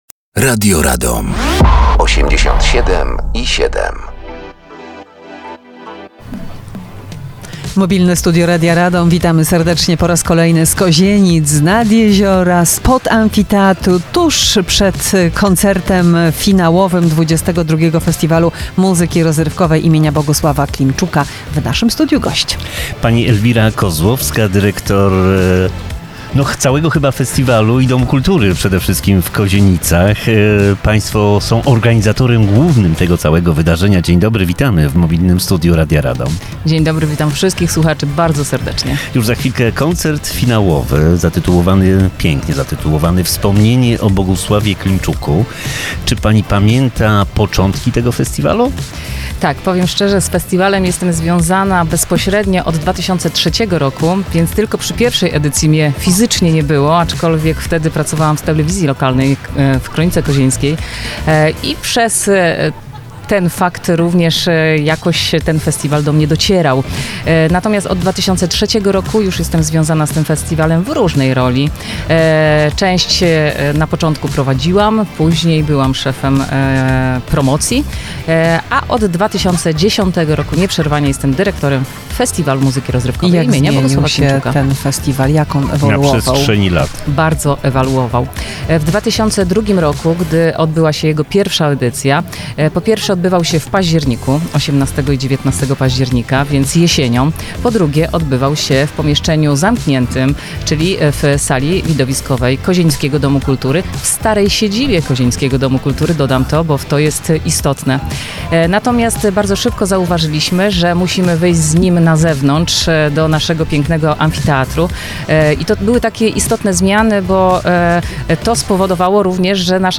Mobilne Studio Radia Radom na XXII Festiwal Muzyki Rozrywkowej im. Bogusława Klimczuka.